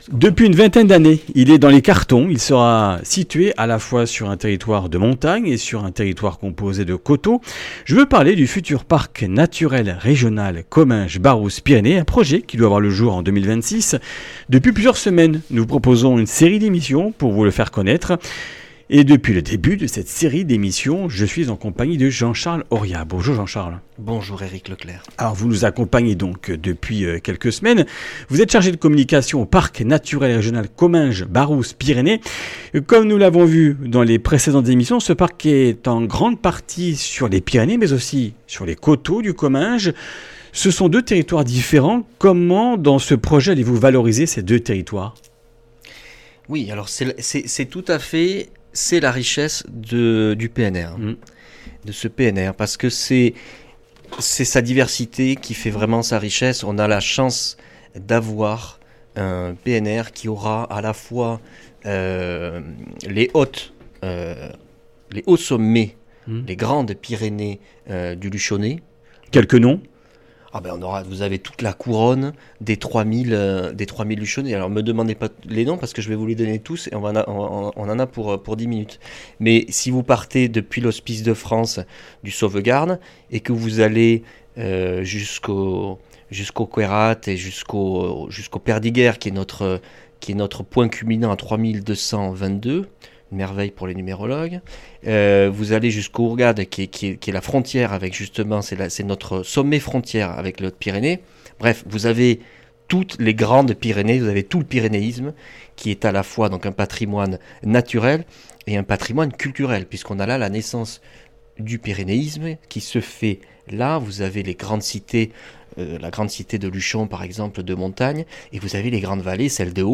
Comminges Interviews du 12 mars